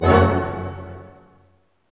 HIT ORCHM0BR.wav